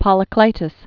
(pŏlĭ-klītəs) fl. fifth century BC.